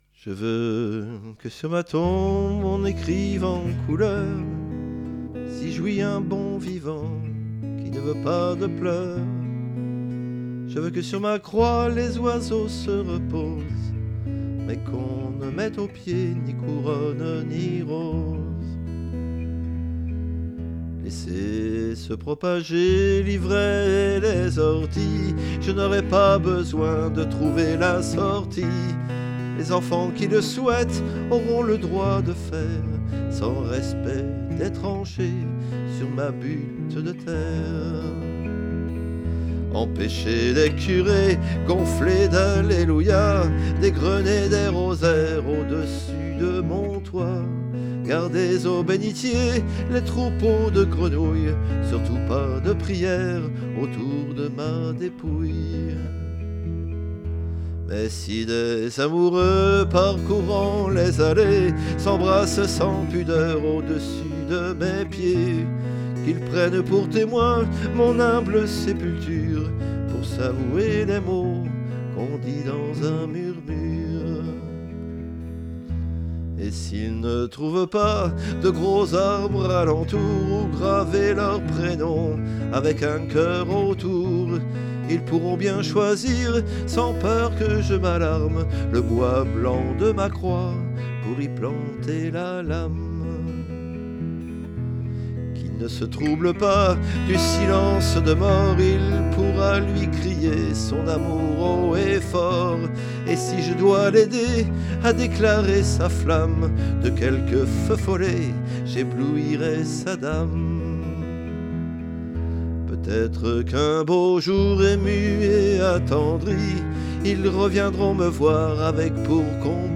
Chant, guitare
Basse, solo